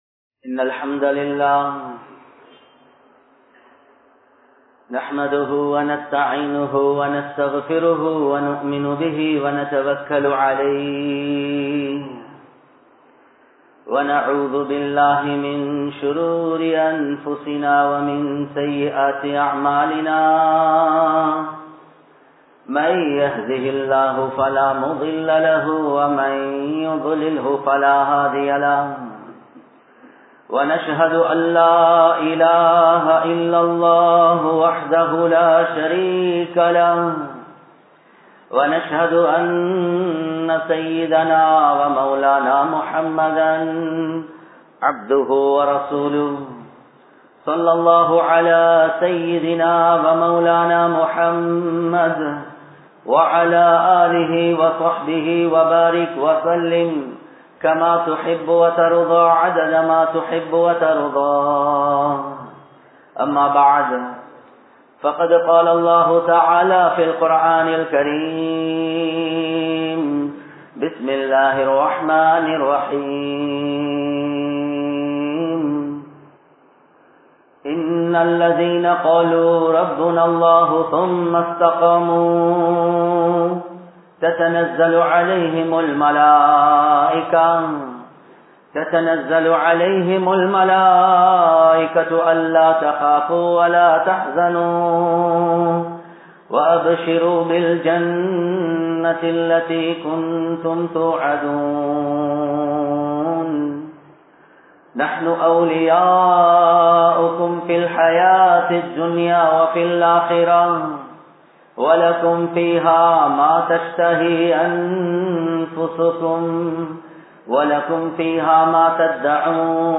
Mulu Naattilum Muslimkalukku Soathanaiya? (முழு நாட்டிலும் முஸ்லிம்களுக்கு சோதனையா?) | Audio Bayans | All Ceylon Muslim Youth Community | Addalaichenai
Colombo 11, Samman Kottu Jumua Masjith (Red Masjith)